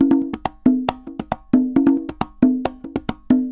Congas_Merengue 136-2.wav